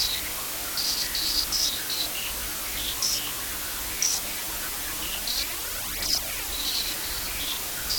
STK_MovingNoiseA-120_03.wav